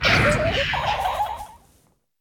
Cri de Scovilain dans Pokémon Écarlate et Violet.